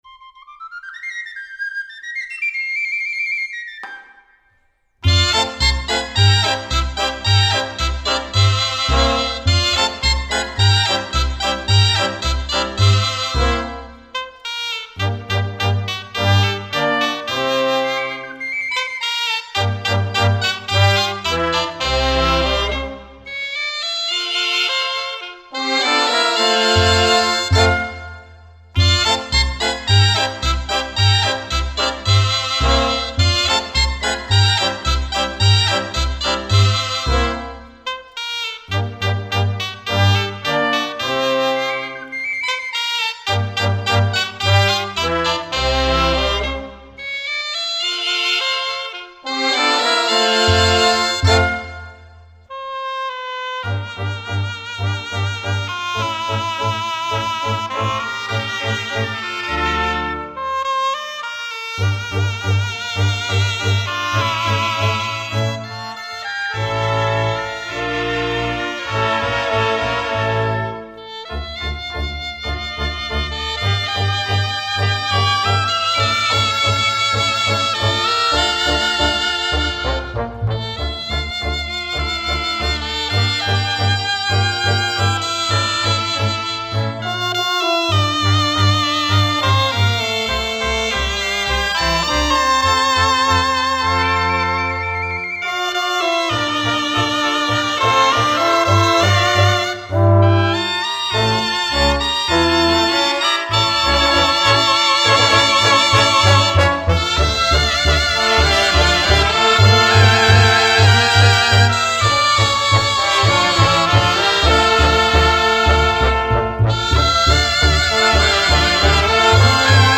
Exemple de l’influencia dels musicals